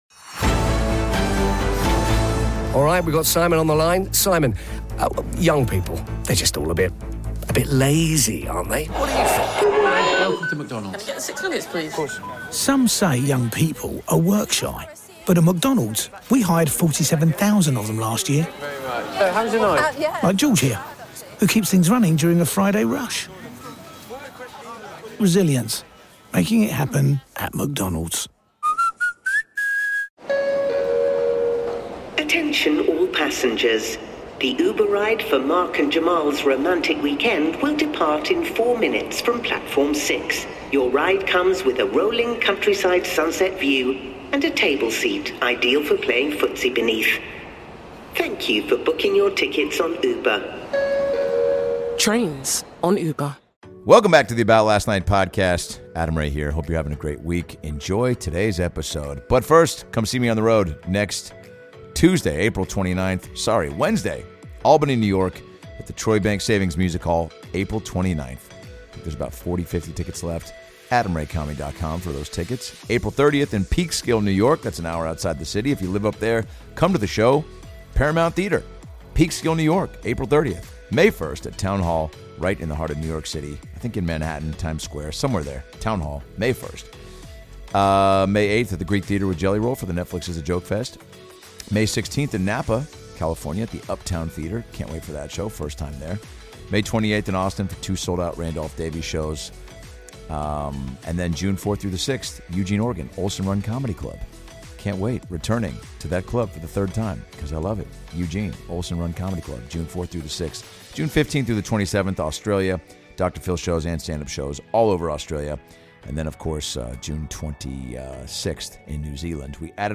Today's episode is "AM I WHITE OR AM I WRONG" a standup special by Joe Biden, featuring Godfrey as Donald Trump.